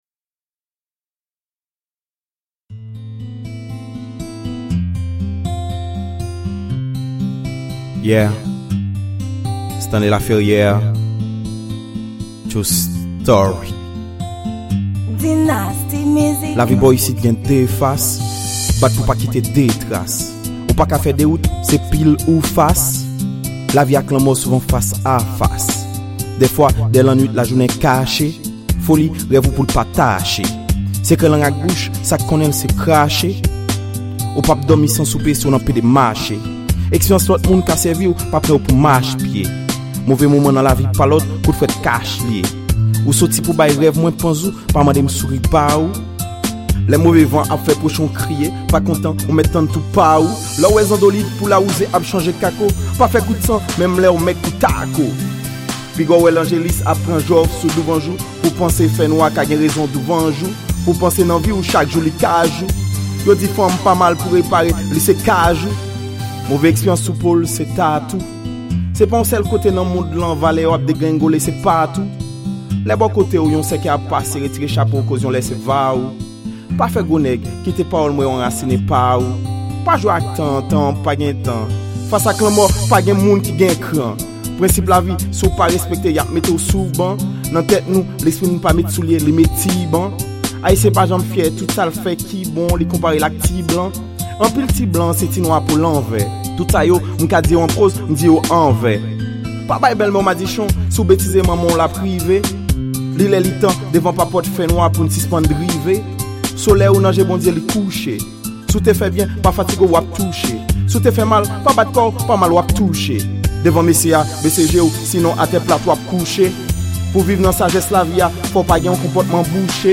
Genre: Slam.